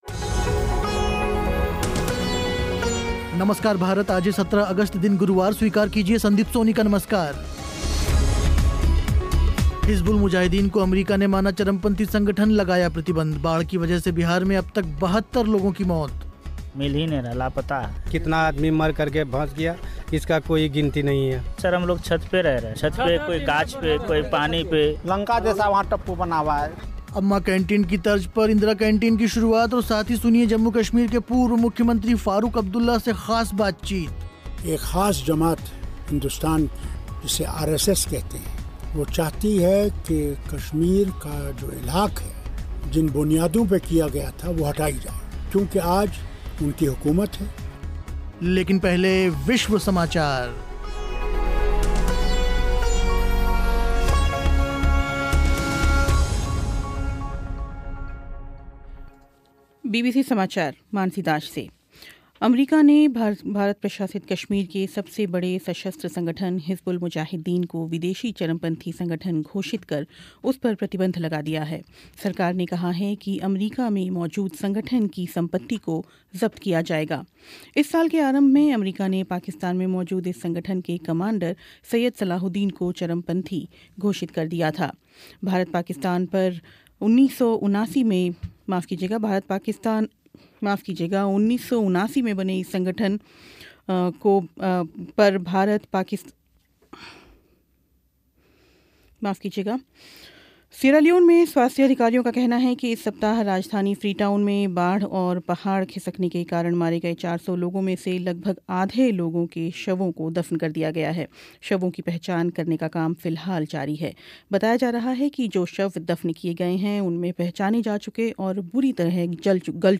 हिज़बुल मुजाहिदीन को अमरीका ने माना चरमपंथी संगठन लगाया प्रतिबंध. सुनिए जम्मू कश्मीर के पूर्व मुख्यमंत्री फ़ारूक़ अब्दुल्ला से ख़ास बातचीत. बाढ़ की वजह से बिहार में अब तक 72 लोगों की मौत. अम्मा कैंटीन की तर्ज़ पर इंदिरा कैंटीन की शुरुआत.